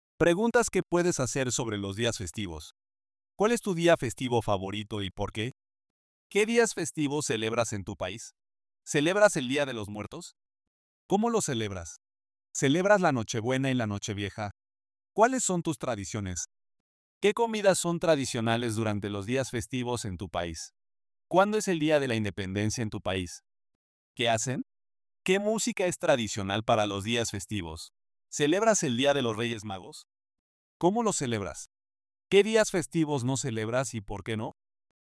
The song in the background is called “El Cantante” by Marc Anthony.